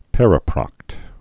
(pĕrə-prŏkt)